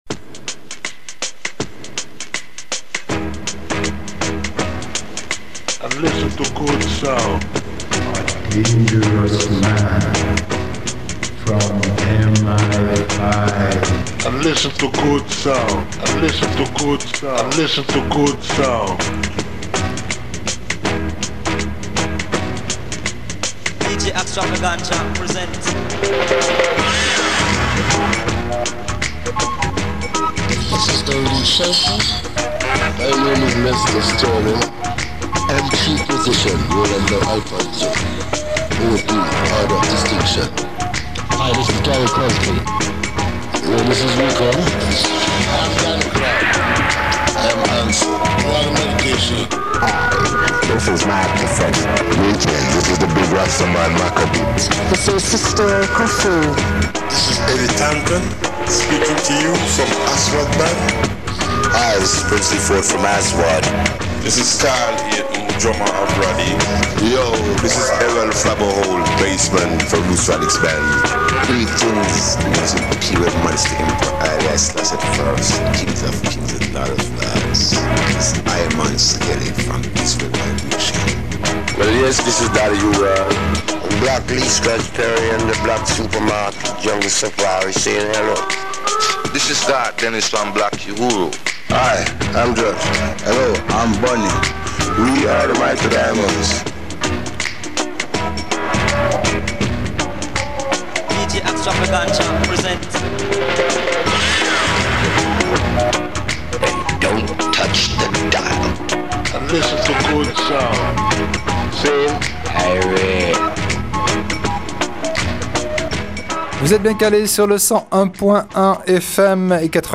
(ska reggae)